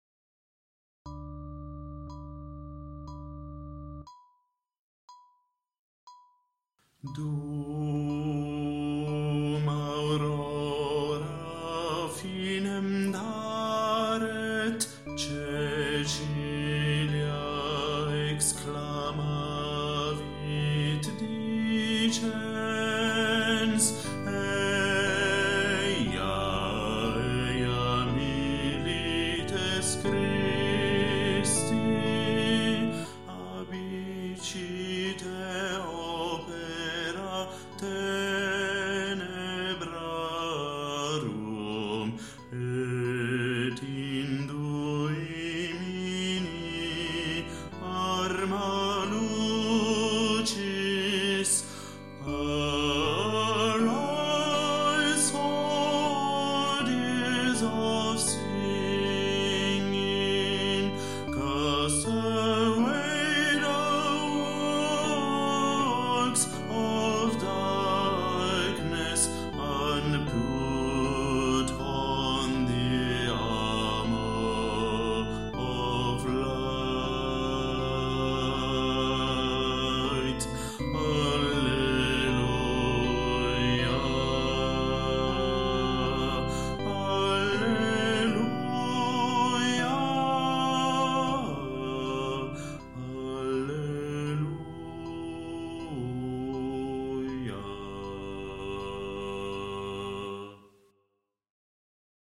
BASSO - Dum Aurora